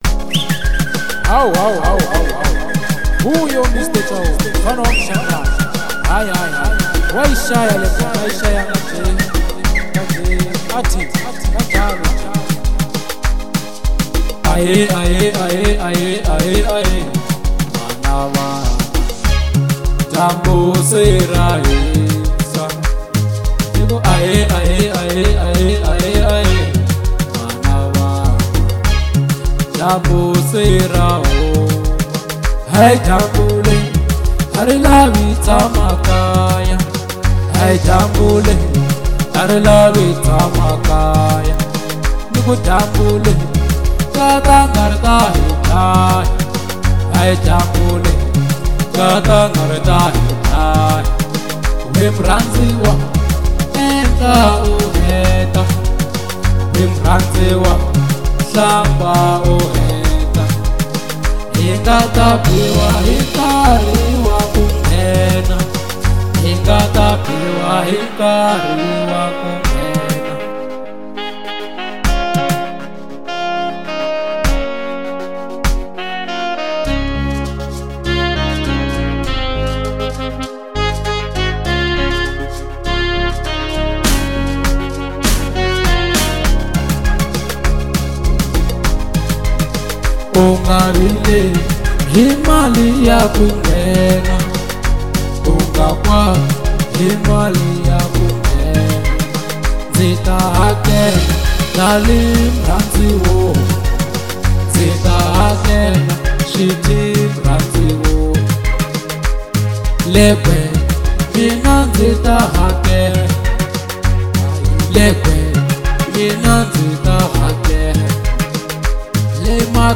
Genre : Local House